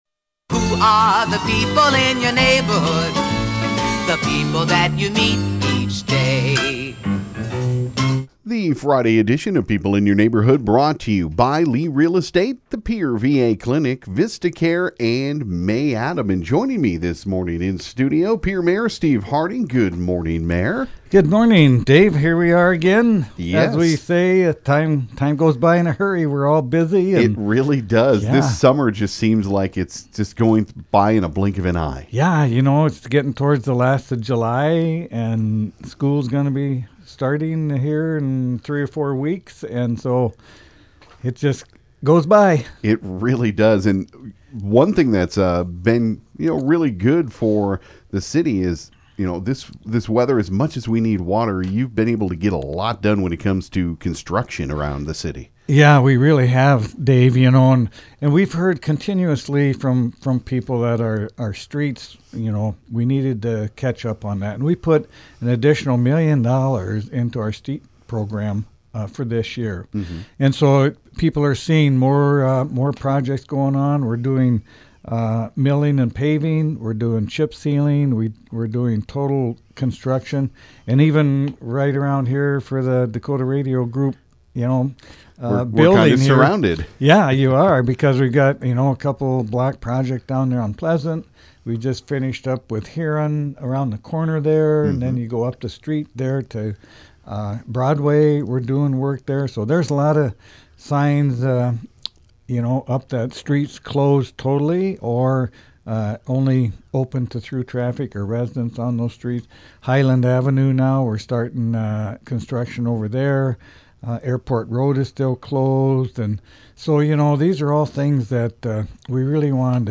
stopped into the KGFX studio